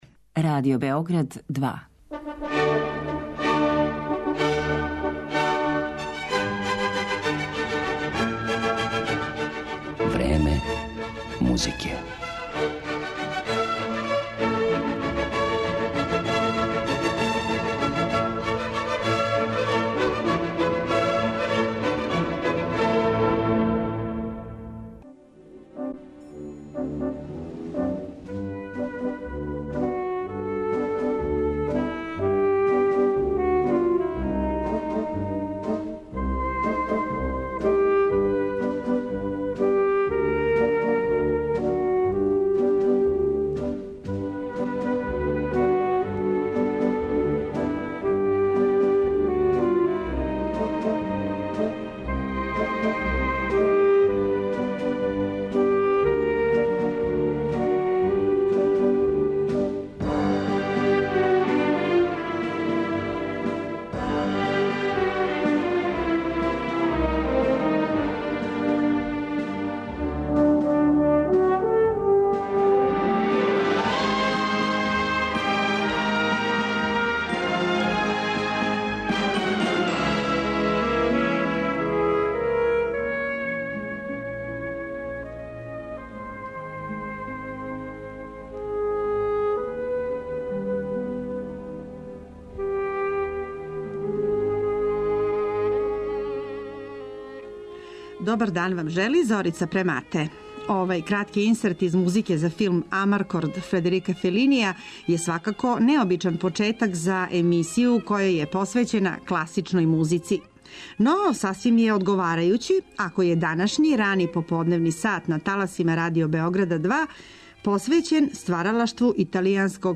Управо овом његовом опусу ћемо и посветити пажњу у данашњој емисији, а емитоваћемо, између осталог, и одломке из његових Прве и Друге симфоније, из 1939, односно, 1941. године.